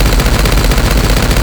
sk_gunshot.wav